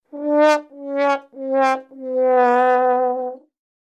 Рингтоны » 3d звуки » Уставший трамбон